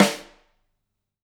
Index of /musicradar/Kit 2 - Acoustic room
CYCdh_K2room_Rim-03.wav